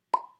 pop1.wav